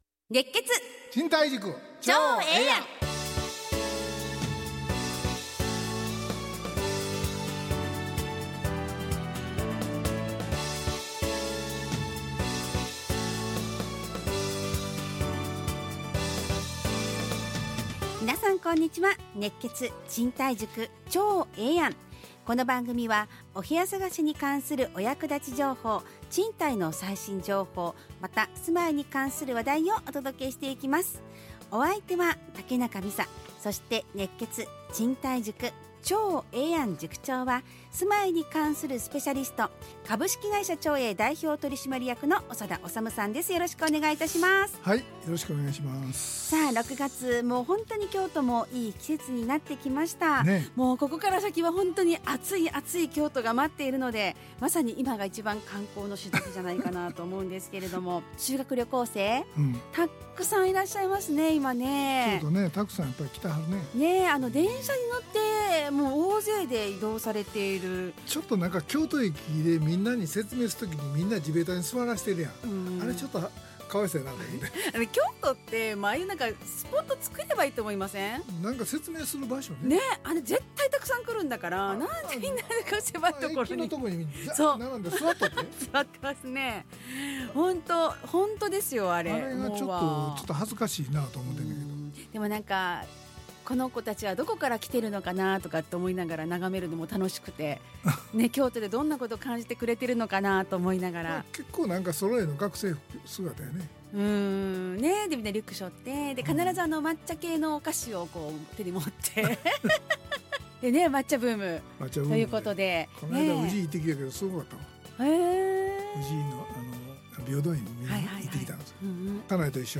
ラジオ放送 2025-06-13 熱血！